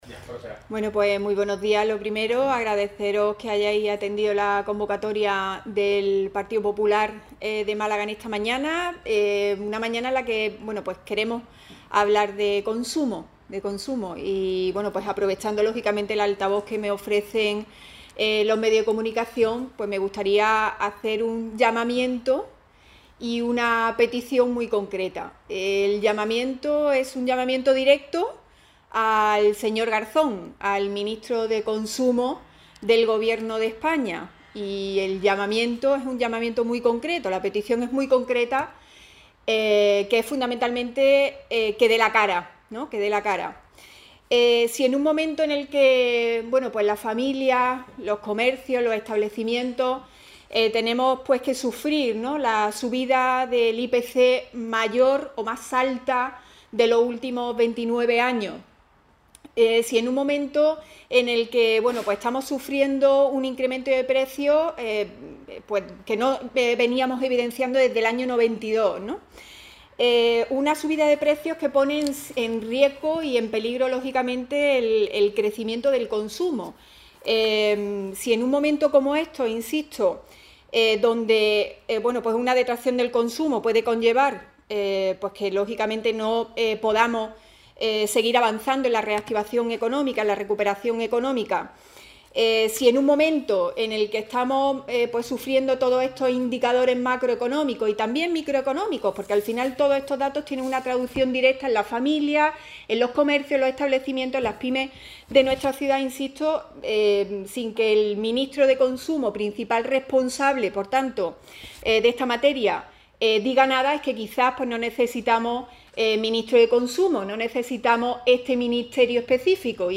Así lo ha expuesto en rueda de prensa, donde ha instado al ministro de Consumo, el malagueño Alberto Garzón, a “dar la cara ante el mayor incremento de precios de los últimos 29 años”.